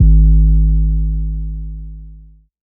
SOUTHSIDE_808_boosh_G#.wav